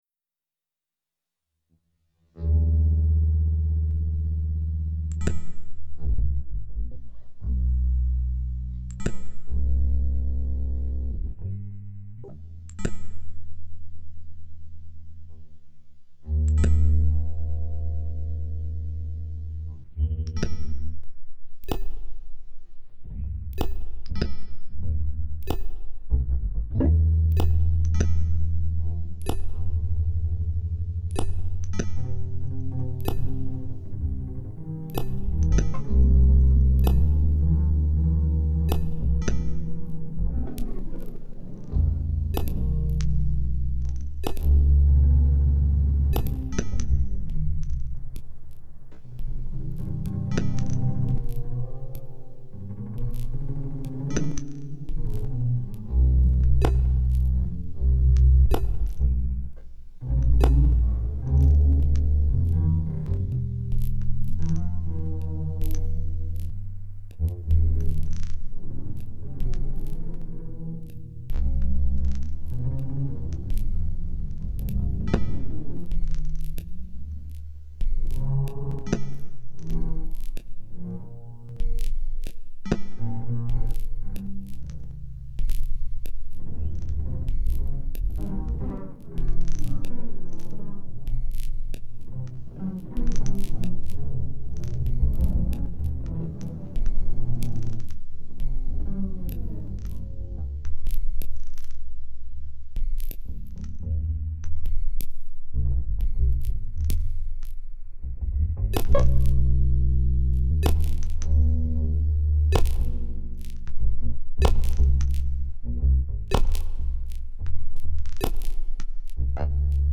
With dark sounds, overexposed, abstractions and allusions, walking distance, low motion, always be.